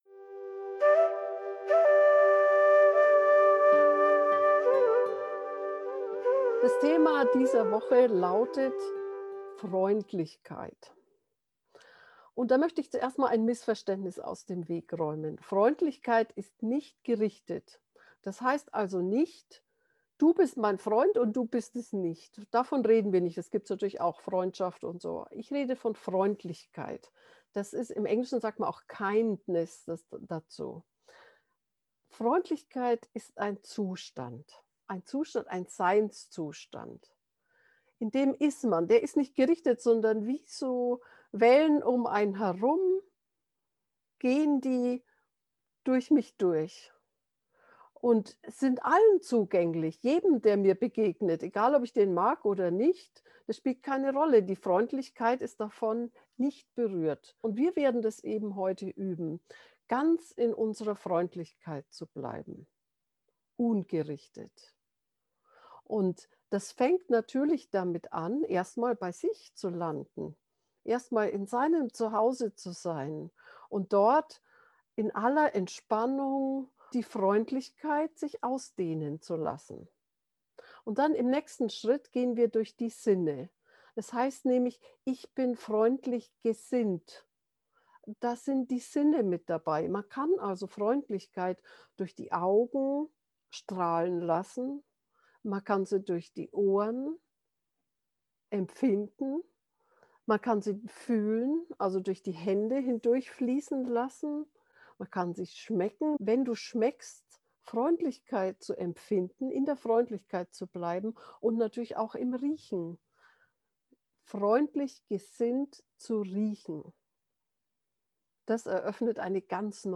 Geführte Meditationen Folge 107: Ein freundliches Leben führen Play Episode Pause Episode Mute/Unmute Episode Rewind 10 Seconds 1x Fast Forward 10 seconds 00:00 / 15:14 Subscribe Share RSS Feed Share Link Embed
freundliches-leben-fuehren-meditation.mp3